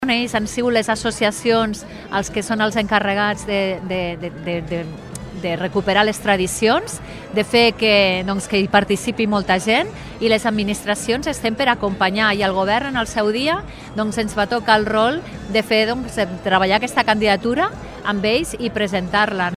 Al seu torn, la ministra La ministra de Cultura, Mònica Bonell, ha detscat la satisfacció veure la bona acollida que ha tingut l’acte.